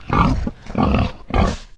boar_idle_3.ogg